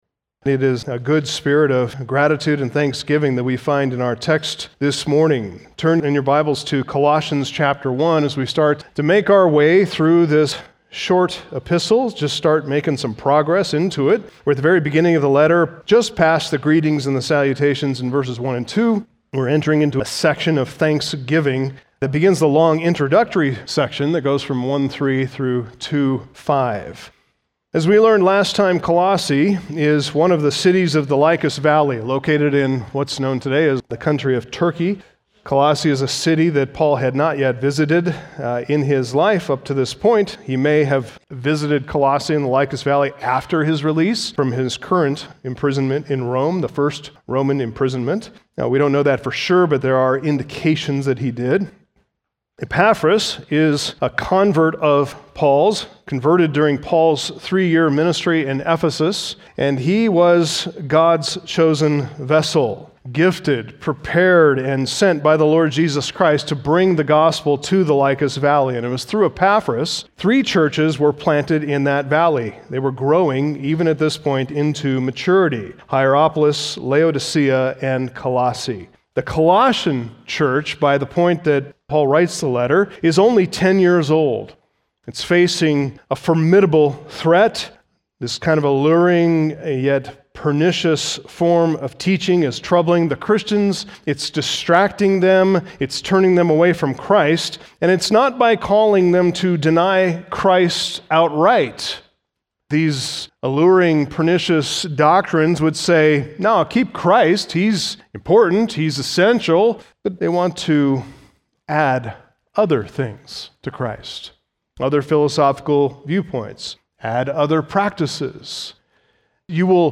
Sermons , Sunday Morning